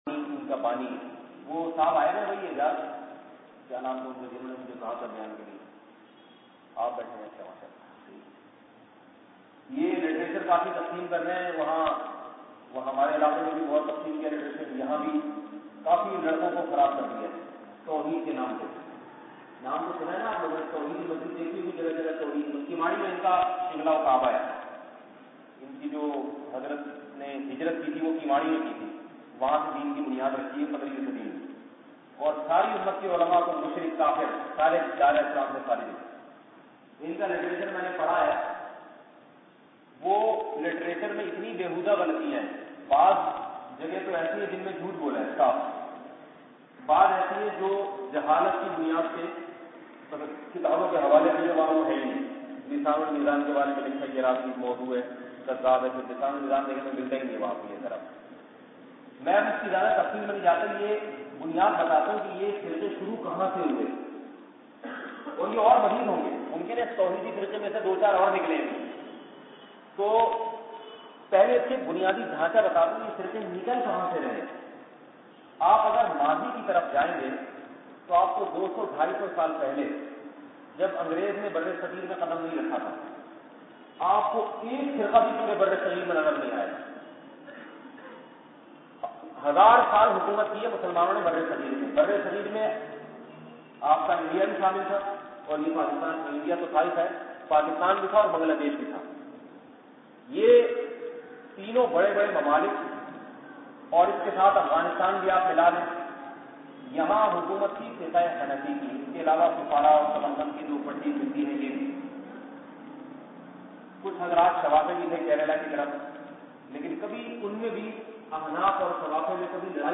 Toheedi Firqa bayan mp3